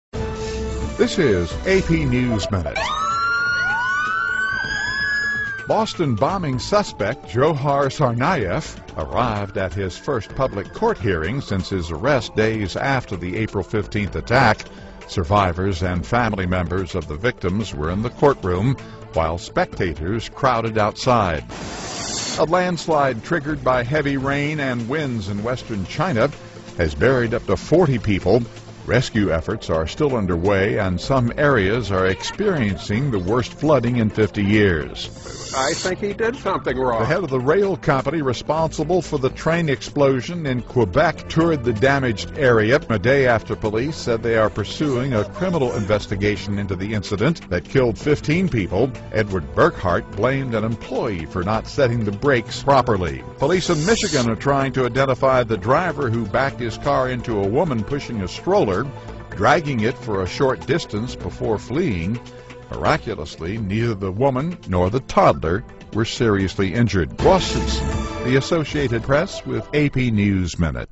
在线英语听力室美联社新闻一分钟 AP 2013-07-14的听力文件下载,美联社新闻一分钟2013,英语听力,英语新闻,英语MP3 由美联社编辑的一分钟国际电视新闻，报道每天发生的重大国际事件。电视新闻片长一分钟，一般包括五个小段，简明扼要，语言规范，便于大家快速了解世界大事。